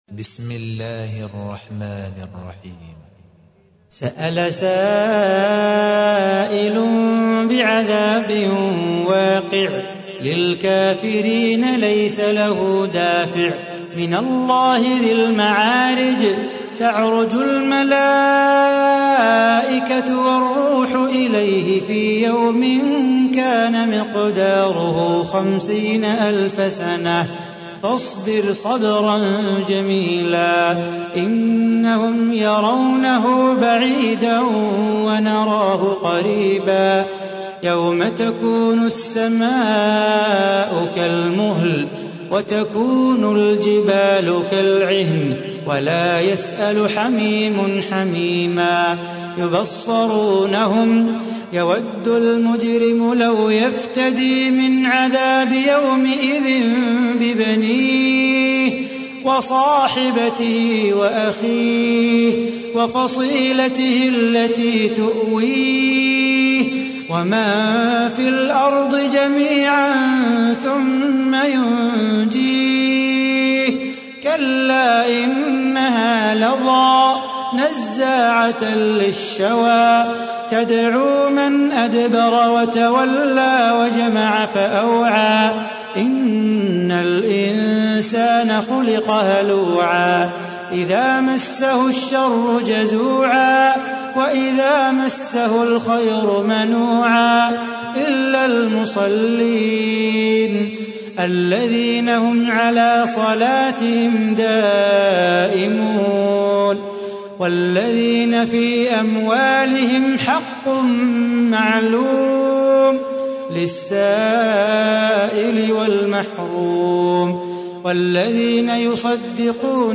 تحميل : 70. سورة المعارج / القارئ صلاح بو خاطر / القرآن الكريم / موقع يا حسين